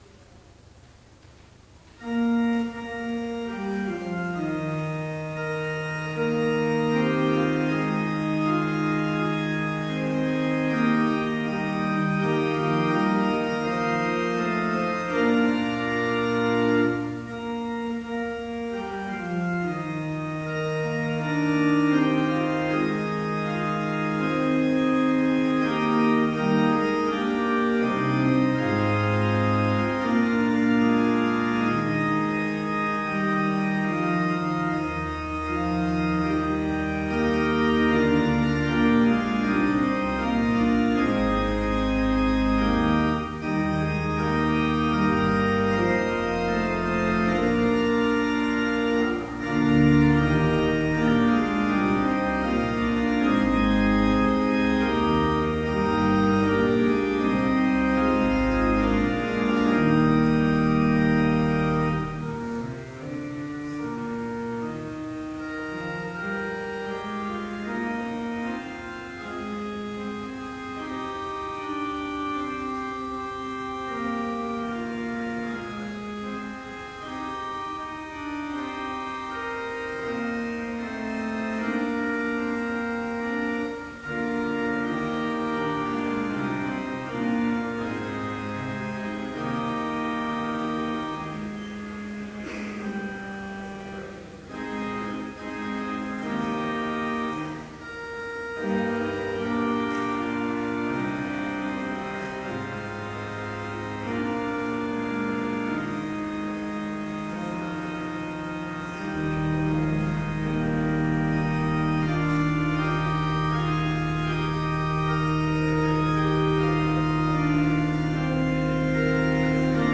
Complete service audio for Chapel - April 9, 2013